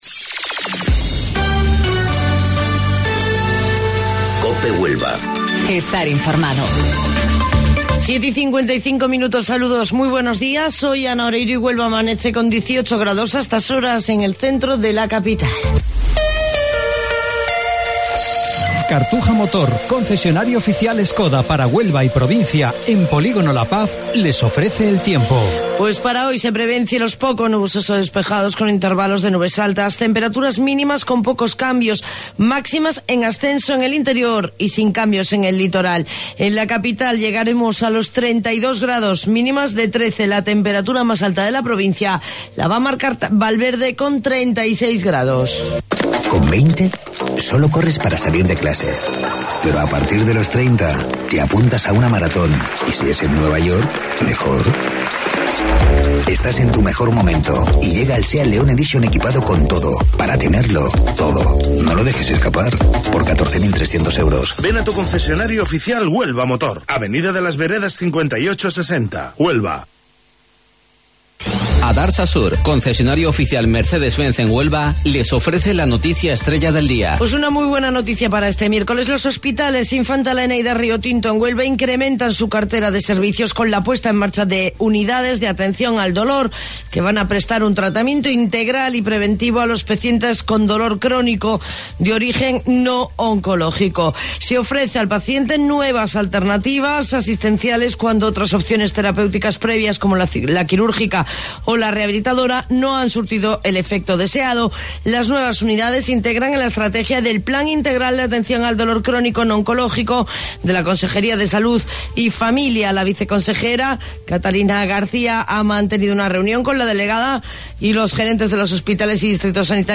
AUDIO: Informativo Local 07:55 del 15 de Mayo